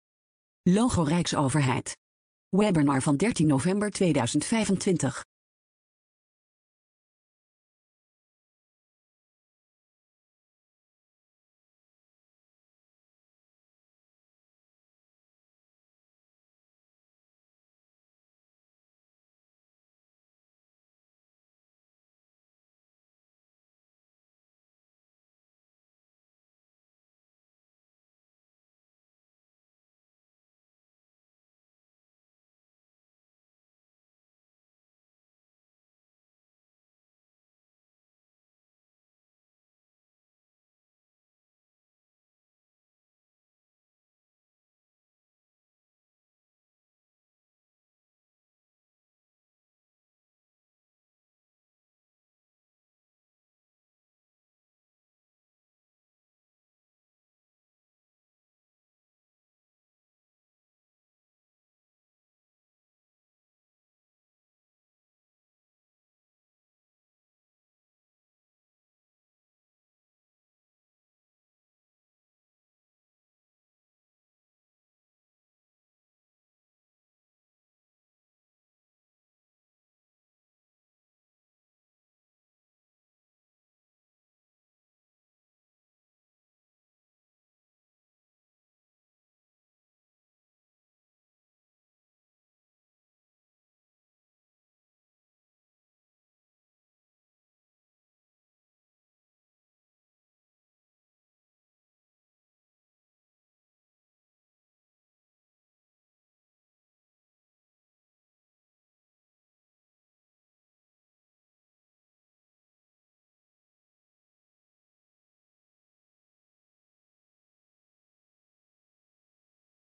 RADIO Webinar: Werken met GenAI als collega | RijksAcademie voor Digitalisering en Informatisering Overheid